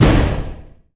IMPACT_Generic_15_mono.wav